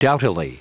Prononciation du mot doughtily en anglais (fichier audio)
Prononciation du mot : doughtily